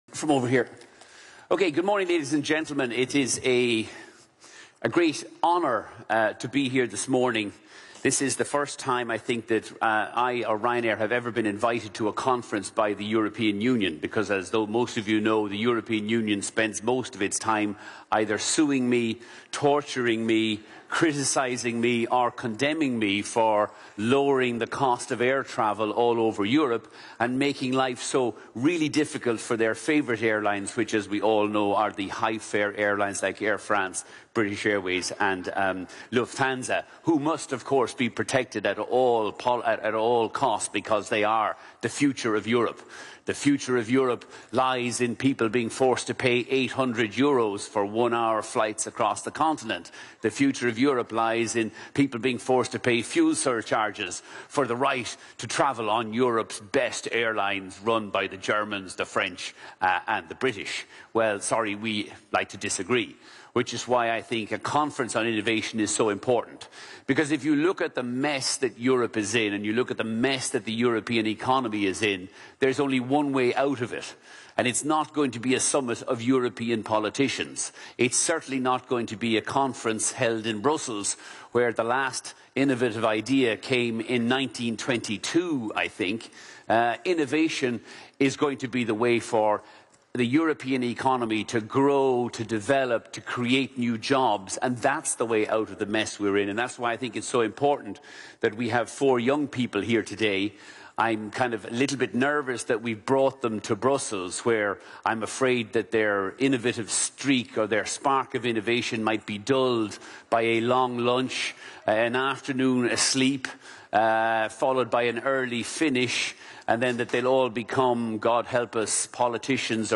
Michael O’Leary at the Innovation Convention 2011 – Brussels – YouTube
Michael-OLeary-at-the-Innovation-Convention-2011-Brussels.mp3